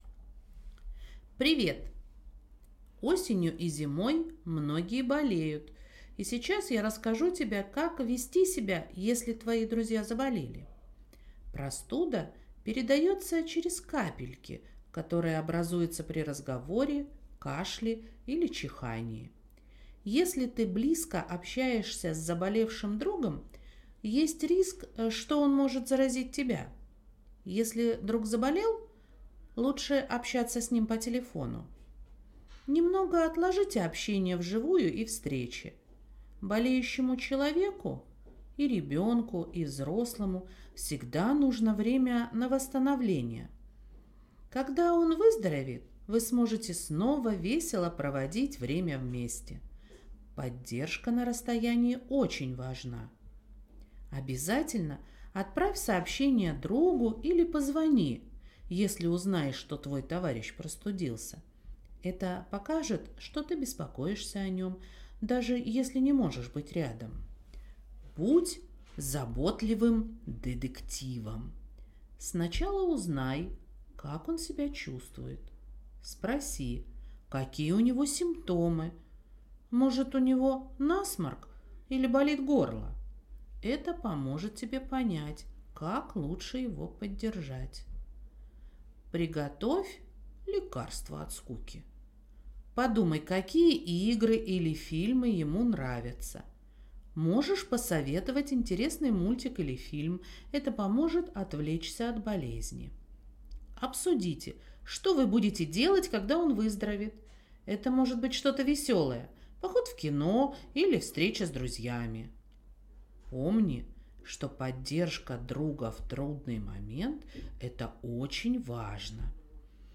Аудиорассказ Что делать если твой друг заболел